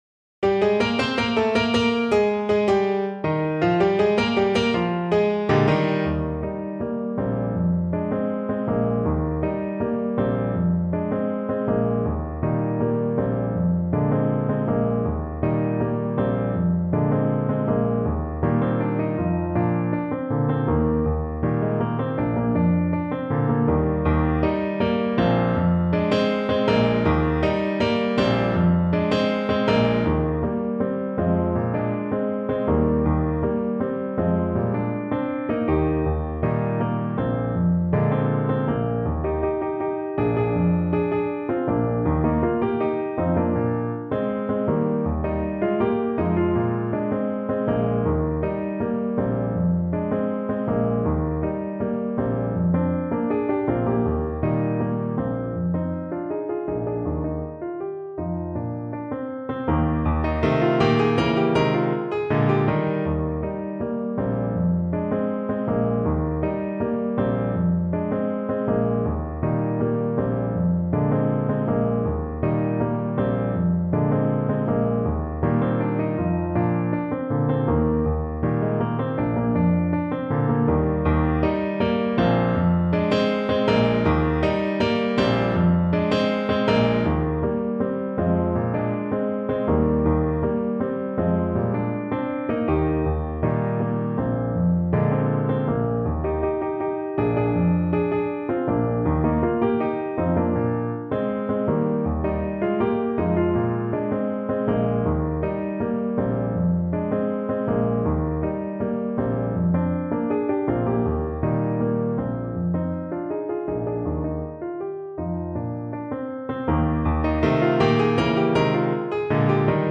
Alto Saxophone
2/2 (View more 2/2 Music)
Allegretto =80
Cuban